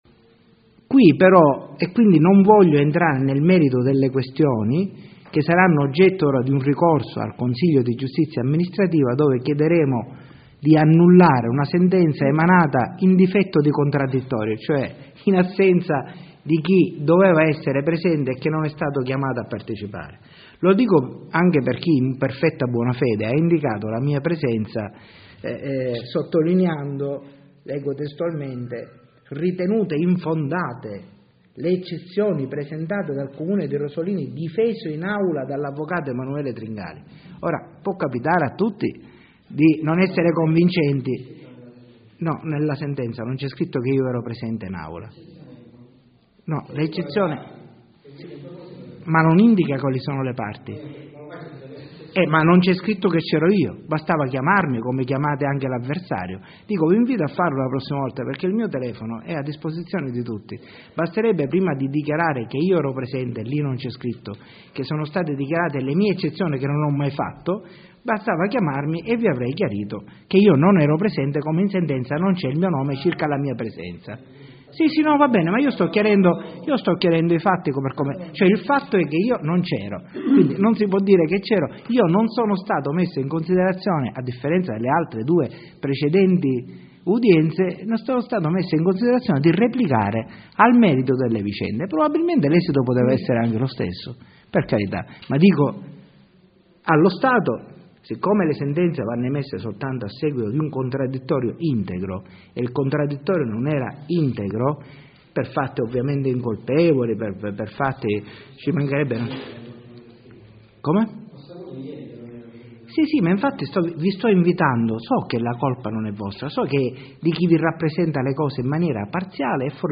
Conferenza stampa Comune Sentenza Tar.mp3 seconda parte